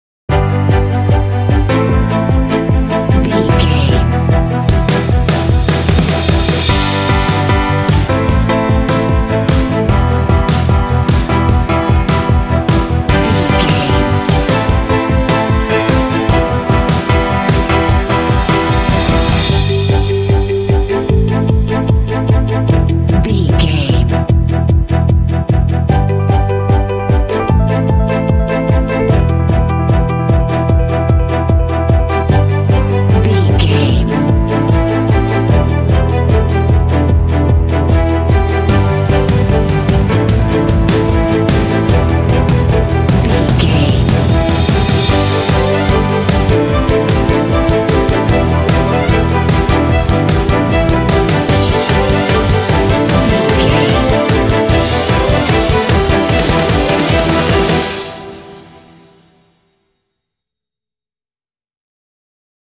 Aeolian/Minor
driving
proud
confident
bright
hopeful
uplifting
elegant
strings
electric guitar
piano
drums
indie
alternative rock